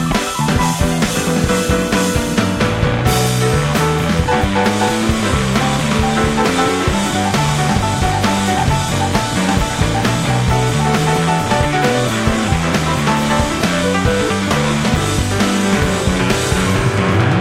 Genre Alternative Rock